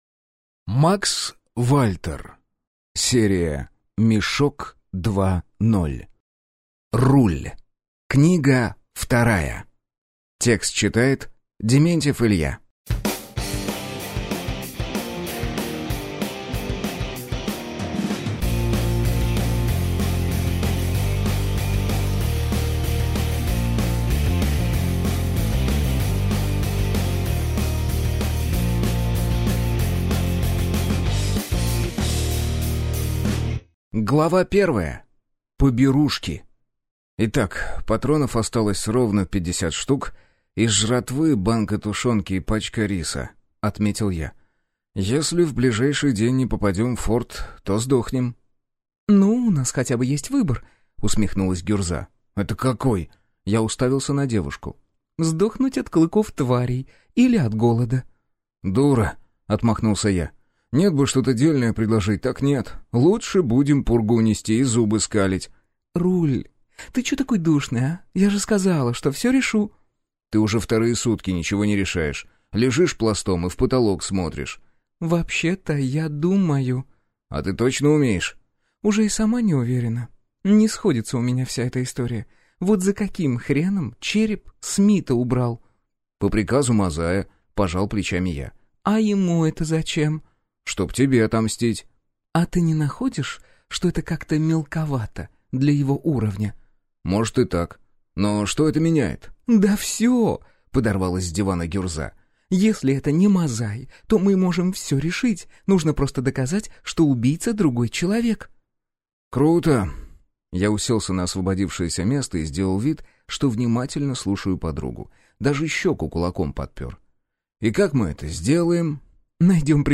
Ревизор: возвращение в СССР 23 (слушать аудиокнигу бесплатно) - автор Серж Винтеркей